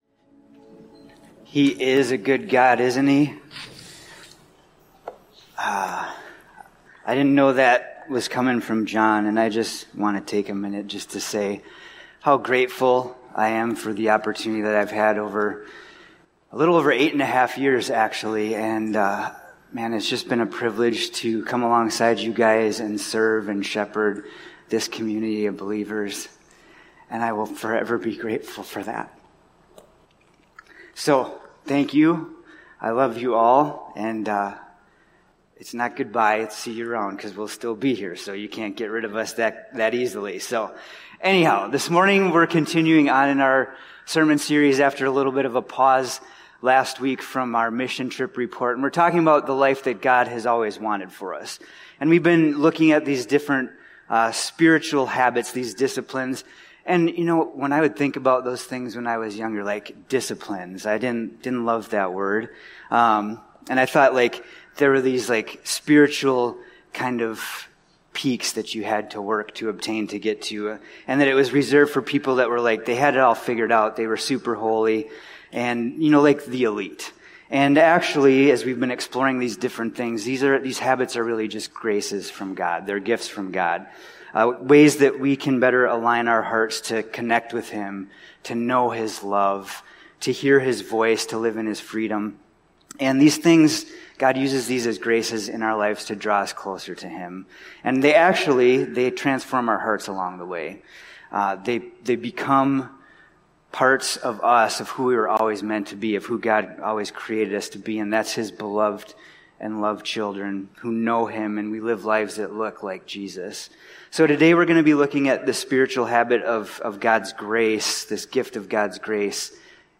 Freedom of the Father (v. 14).This podcast episode is a Sunday message from Evangel Community Church, Houghton, Michigan, June 29, 2025.